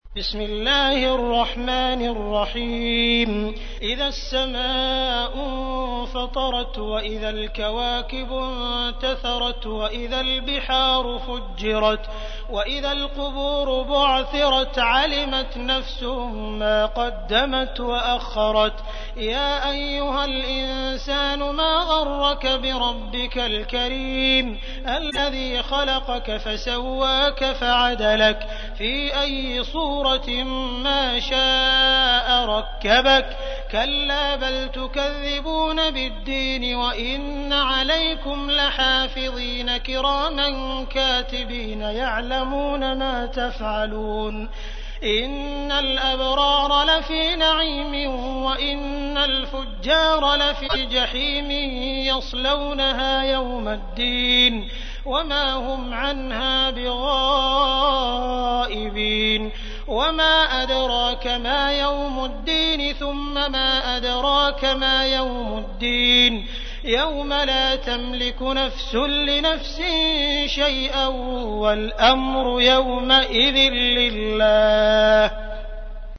تحميل : 82. سورة الانفطار / القارئ عبد الرحمن السديس / القرآن الكريم / موقع يا حسين